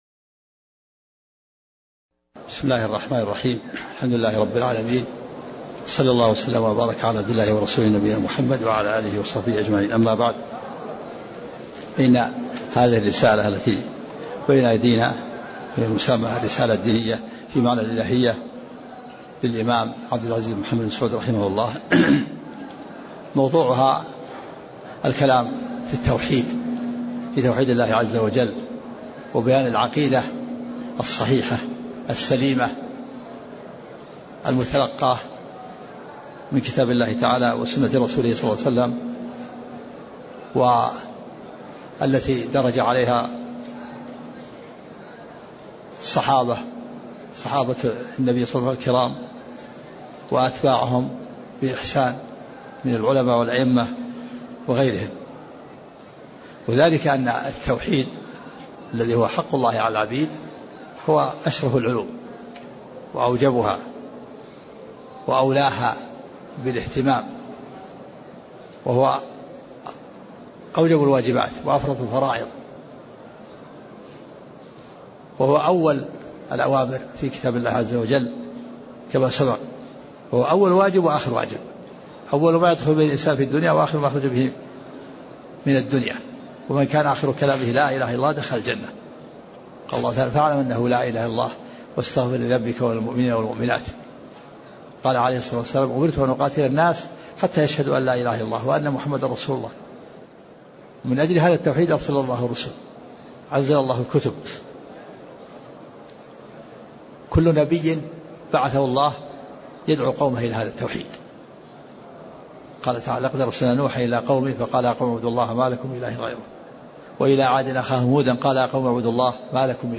تاريخ النشر ٥ شعبان ١٤٣٤ هـ المكان: المسجد النبوي الشيخ